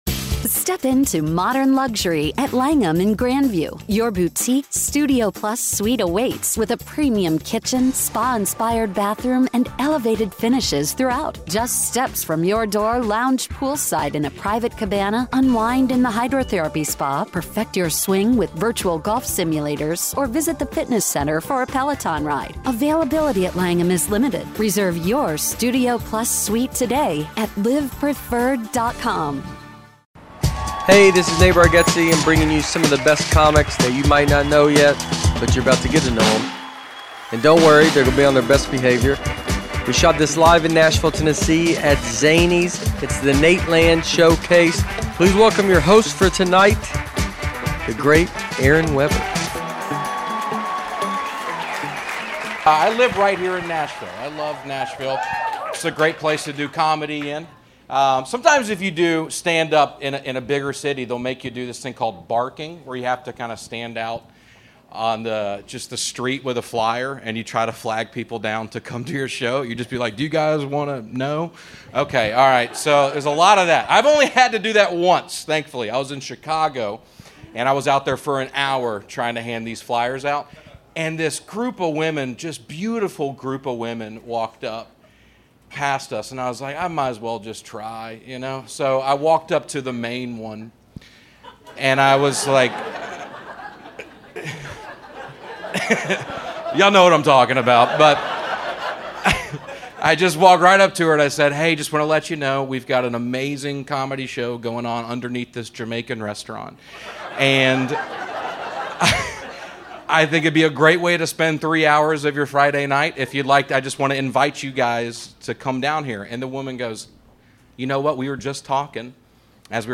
The Showcase features several of the best comics that you might not know yet, but you're about to get to to know 'em. Hosted by the members of The Nateland Podcast and directed by Nate Bargatze himself, The Showcase was recorded live at Zanies Comedy Club in Nashville, Tennessee. Standup comedy at its best.